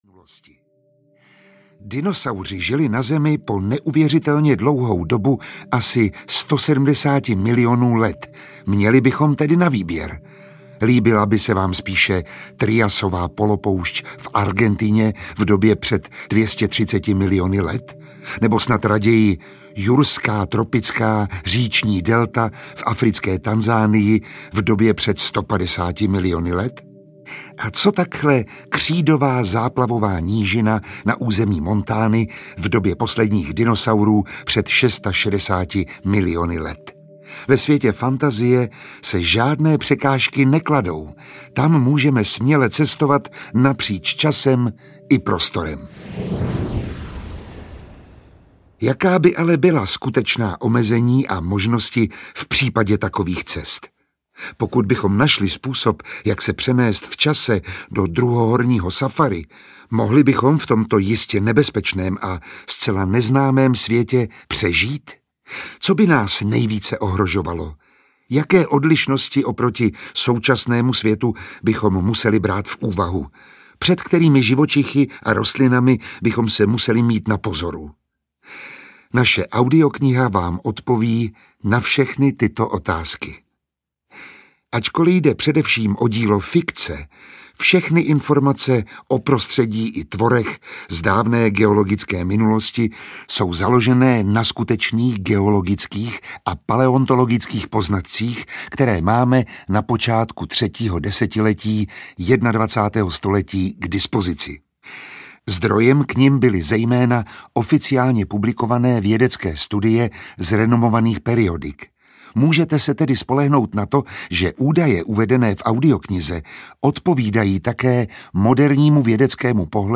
Ukázka z knihy
Audiokniha představuje více než čtyřicet druhů dinosaurů a dalších pravěkých živočichů. Zajímavé informace o životě v druhohorách doplňuje řada zvukových efektů, takže poslech nahrávky je mimořádně napínavým zážitkem pro malé i velké posluchače.
Skvělým průvodcem a vypravěčem je herec Miroslav Táborský.